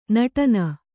♪ naṭana